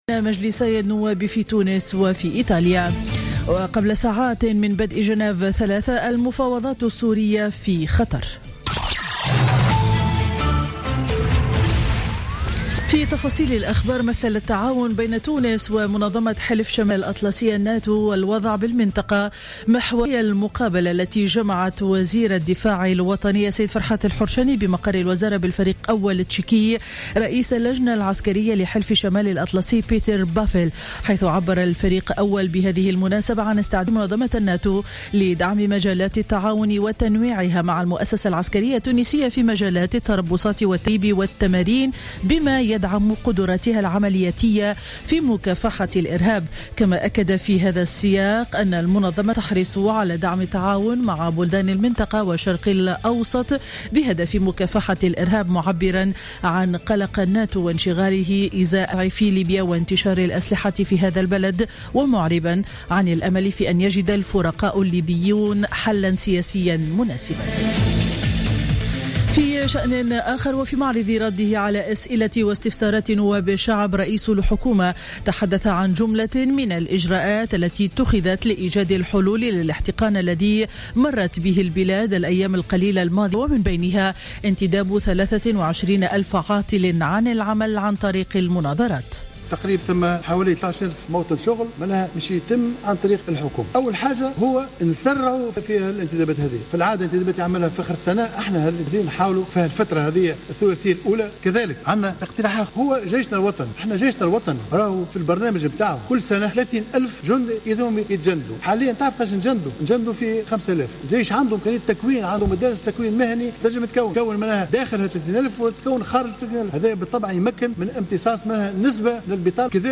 Journal Info 07h00 du vendredi 29 janvier 2016